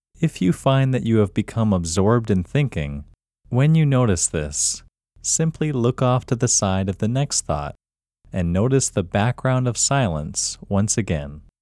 QUIETNESS Male English 14
The-Quietness-Technique-Male-English-14.mp3